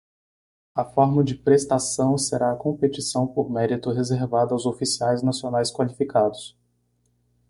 Read more merit (something worthy of positive recognition) Frequency C1 Pronounced as (IPA) /ˈmɛ.ɾi.tu/ Etymology From Latin meritum.